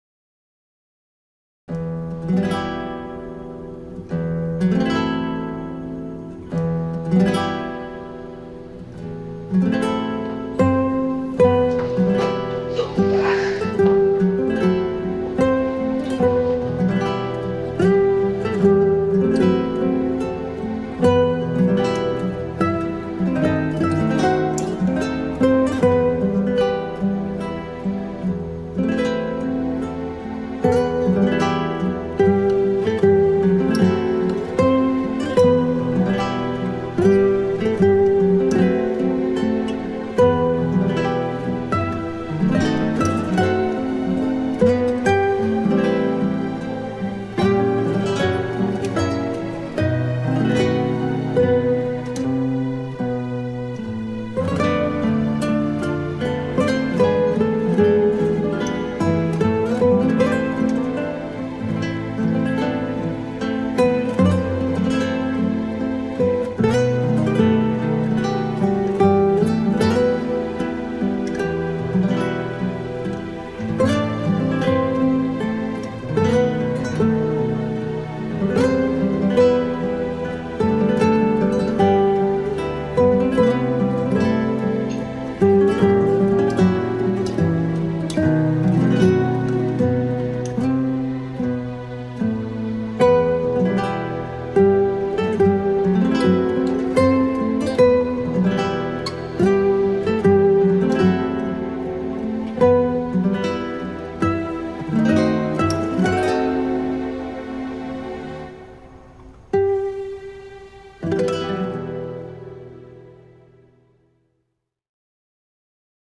duygusal hüzünlü üzgün fon müziği.